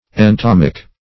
Search Result for " entomic" : The Collaborative International Dictionary of English v.0.48: Entomic \En*tom"ic\, Entomical \En*tom"ic*al\, a. [Gr.